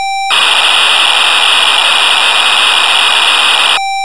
Juno-106 Factory Preset Group B